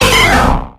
Audio / SE / Cries / POLIWRATH.ogg